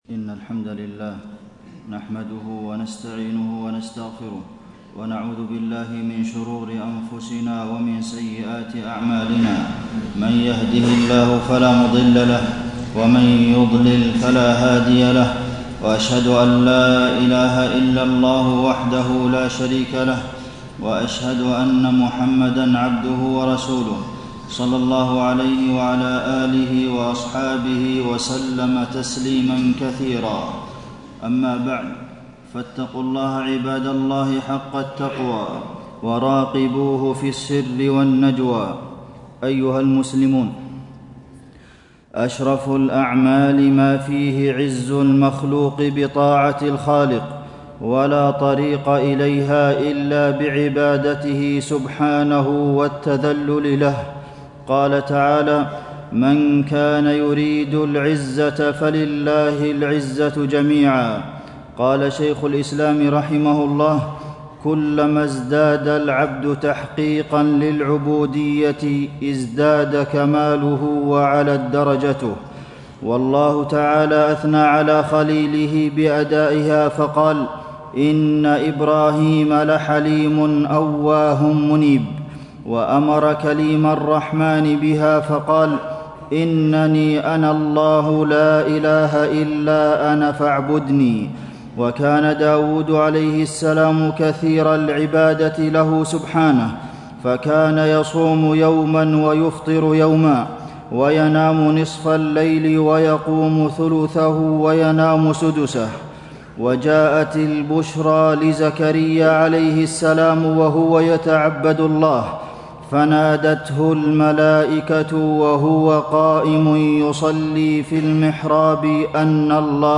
تاريخ النشر ١٩ شعبان ١٤٣٤ هـ المكان: المسجد النبوي الشيخ: فضيلة الشيخ د. عبدالمحسن بن محمد القاسم فضيلة الشيخ د. عبدالمحسن بن محمد القاسم رمضان موسم الخير والبركة